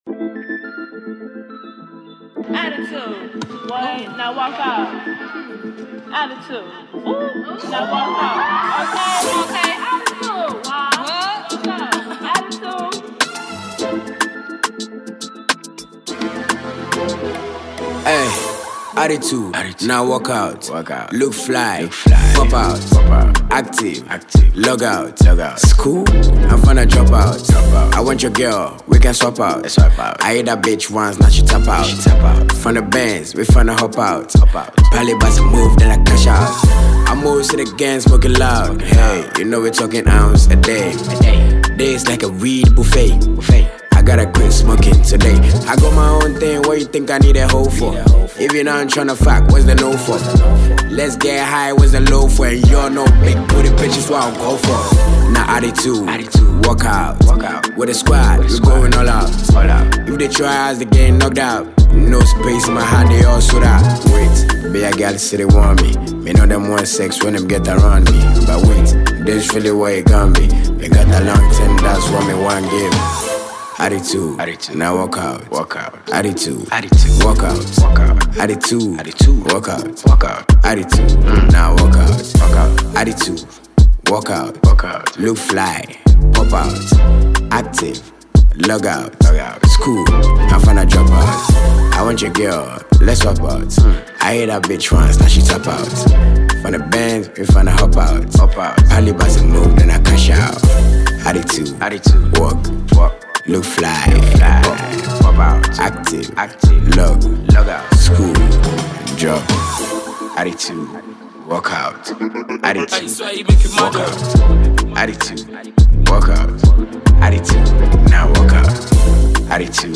a creative Ghanaian rapper
This is a banger all day.